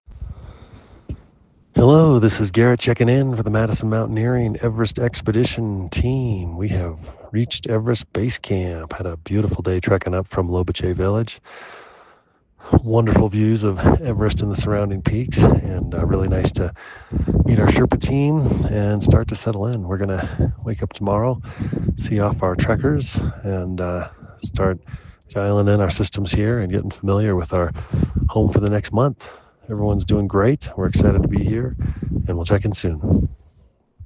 checks in with this dispatch from Nepal: